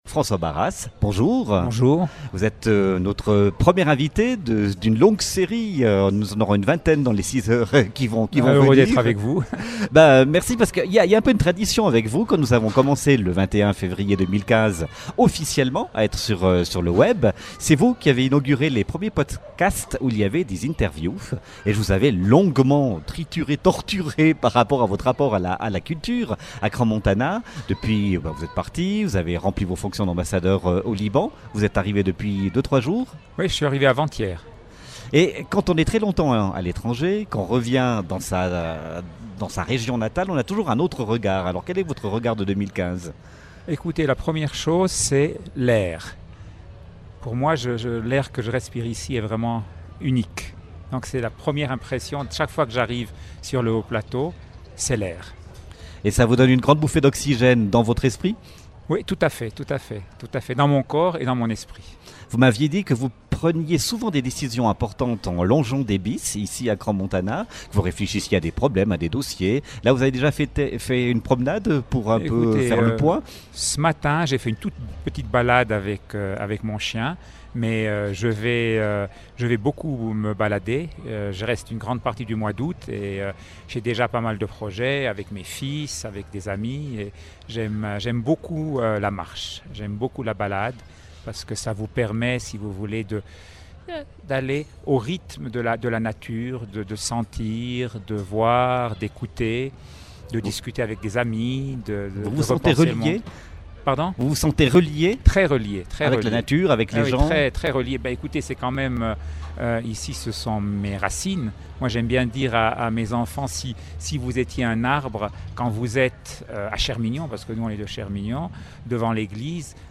Radio Crans-Montana était en direct du marché à Crans-Montana le 31 juillet. L'occasion de rencontrer de nombreux acteurs de la station.